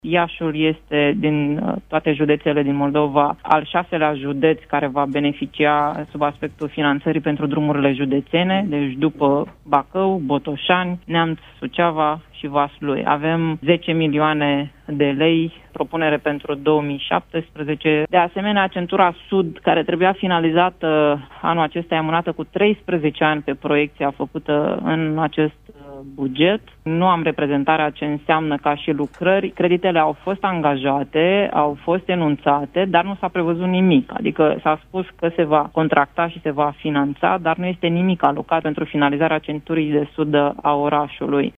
Vicepreședintele Senatului, Iulua Scântei, parlamentar liberal de Iași, a făcut această declarație în emisiunea Imperativ, la postul nostru de radio:
28-ian-Iulia-Scantei-drumuri.mp3